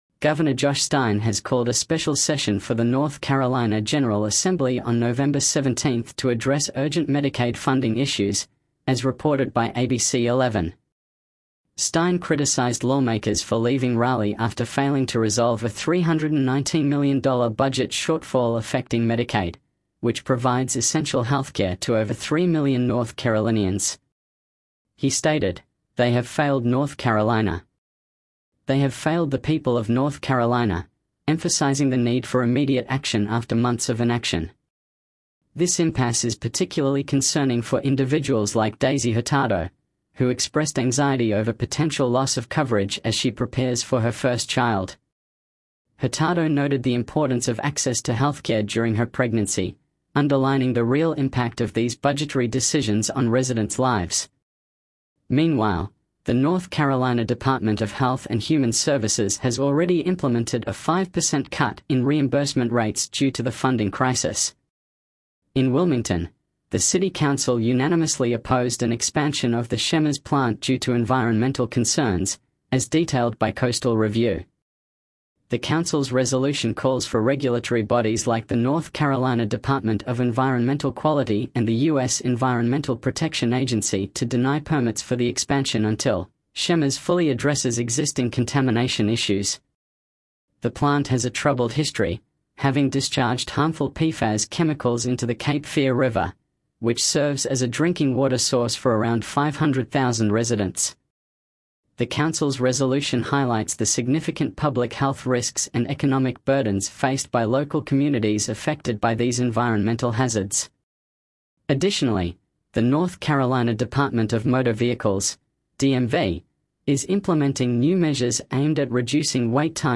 North Carolina News Summary